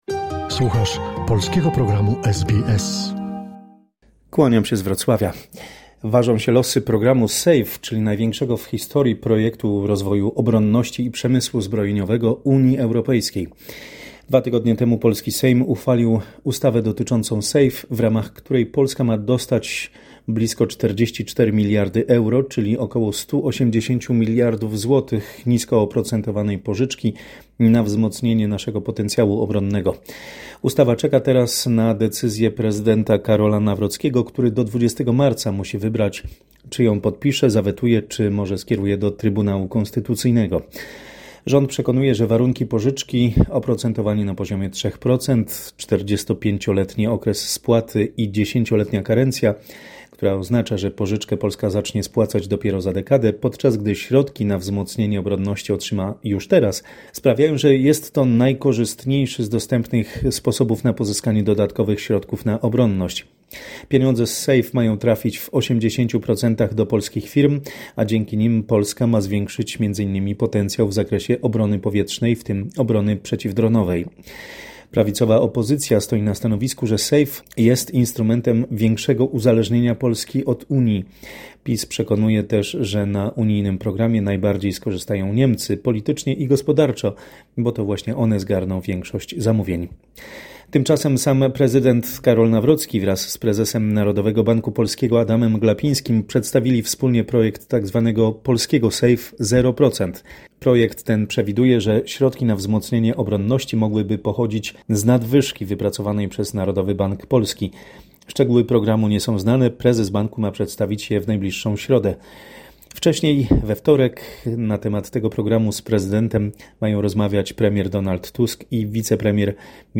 W korespondencji z Polski: ważą się losy programu SAFE, ustawa czeka na decyzję prezydenta Karola Nawrockiego; na lotnisku wylądowały trzy samoloty z obywatelami polskimi ewakuowanymi z Kataru, wróciło 288. pasażerów.